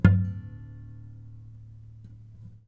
strings_harmonics
harmonic-09.wav